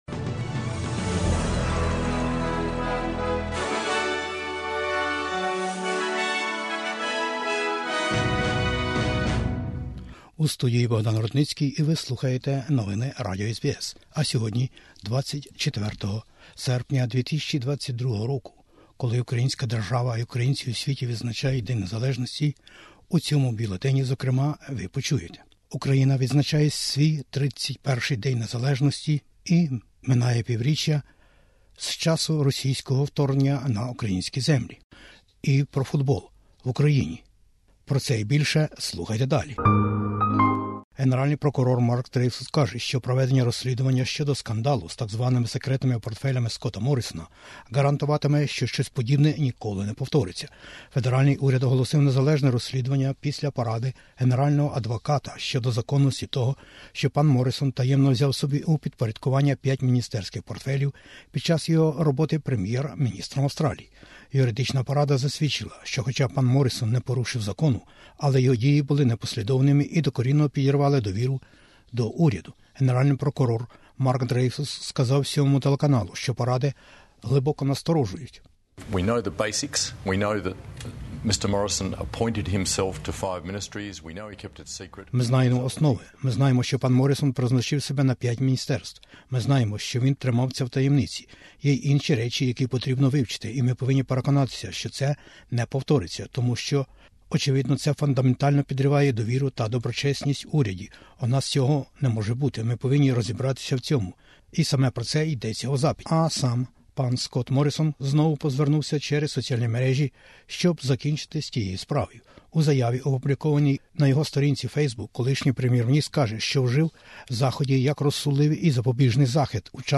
Бюлетень SBS новин - 24/08/2022